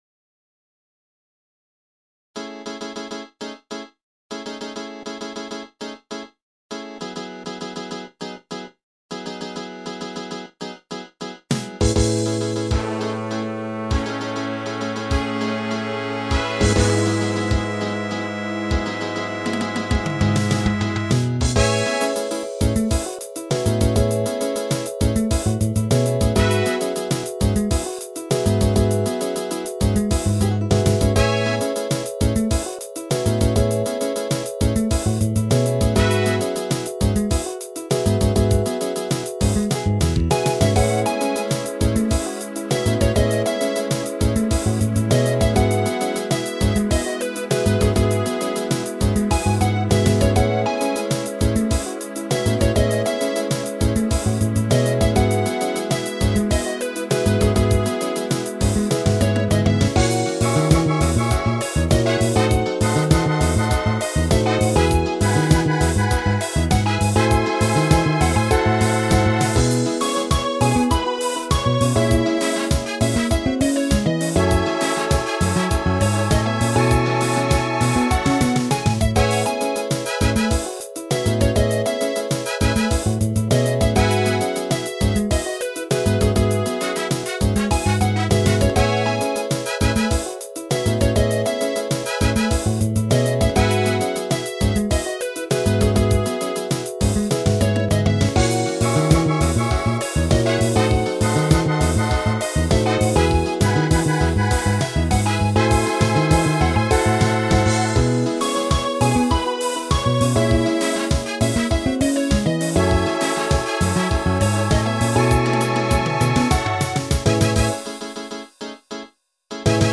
単調な曲だが我々世代のギタリストはこぞって練習した曲。
ブラスはなんとなくの雰囲気でテキトー。